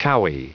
Prononciation du mot cowy en anglais (fichier audio)
Prononciation du mot : cowy